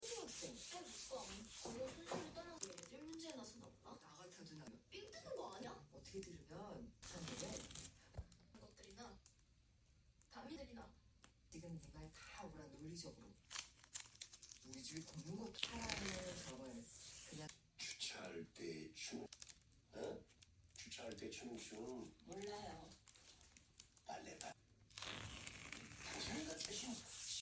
rose jam hard wax leg waxing with TV background sounds